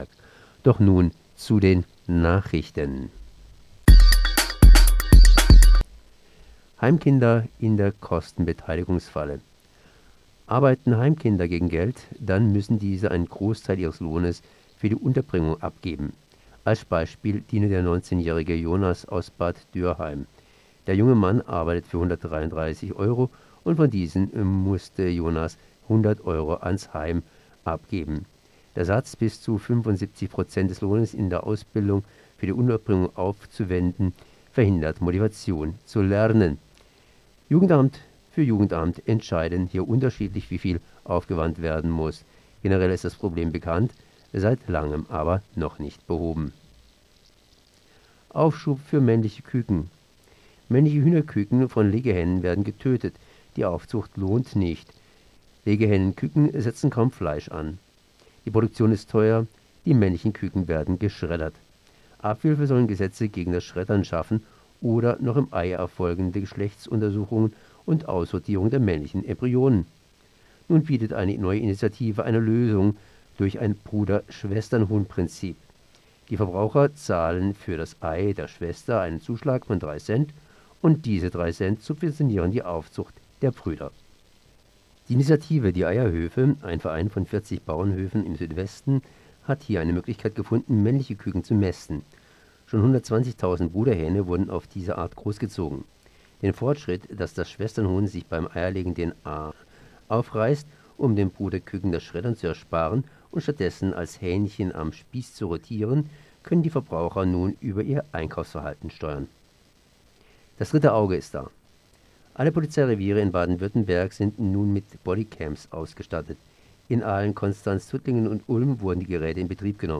Fokus Südwest 13.06.2019 Nachrichten